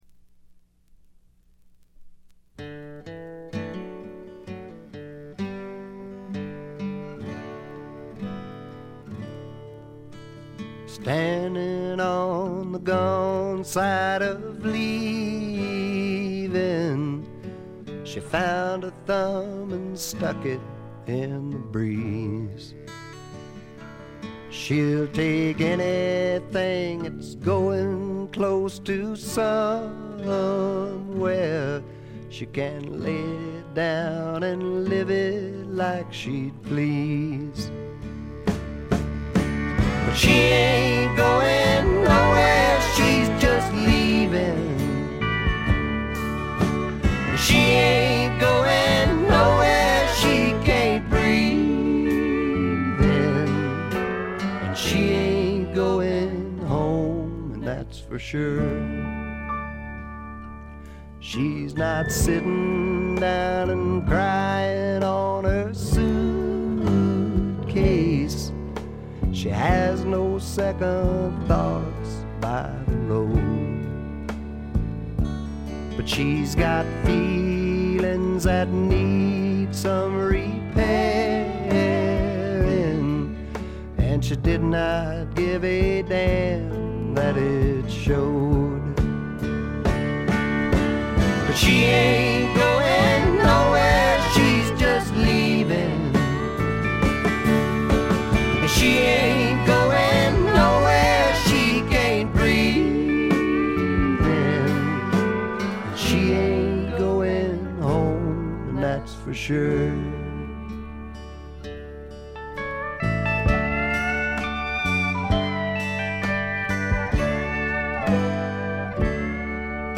ほとんどノイズ感無し。
朴訥な歌い方なのに声に物凄い深さがある感じ。
試聴曲は現品からの取り込み音源です。
Guitar, Vocals